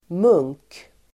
Uttal: [mung:k]